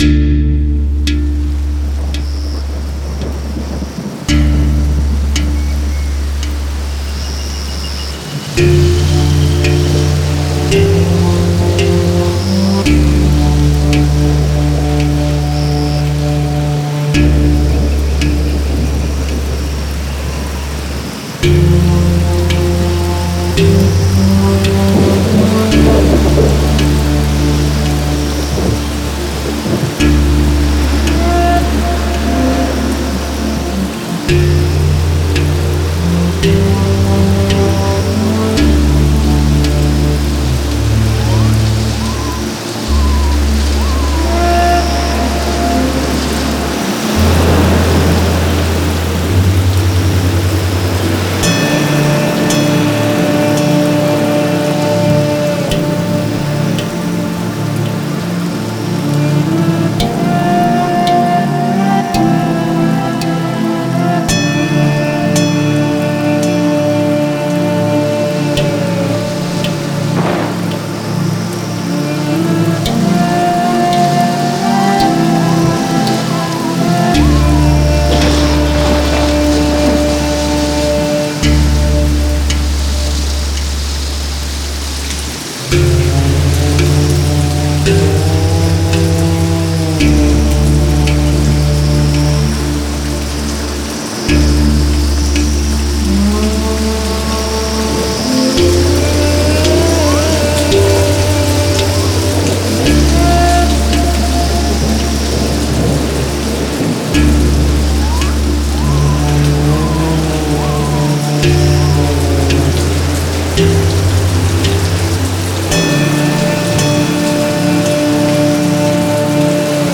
Genre: Ambient, New Age.